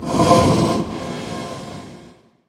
Minecraft Version Minecraft Version 25w18a Latest Release | Latest Snapshot 25w18a / assets / minecraft / sounds / mob / blaze / breathe3.ogg Compare With Compare With Latest Release | Latest Snapshot
breathe3.ogg